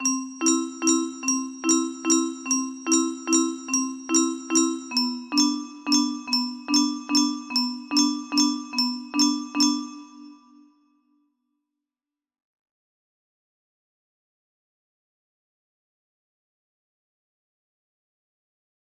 Clowning around music box melody
Full range 60